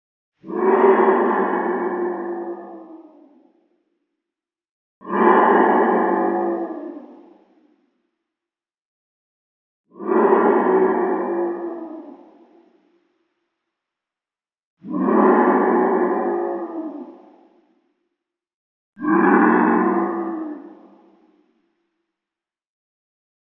Хотя его существование не доказано, эти аудиозаписи помогут вам представить его голос: от глухого рычания до странных шорохов.
Жуткий звук леденящего крика снежного чудовища из ледяной пещеры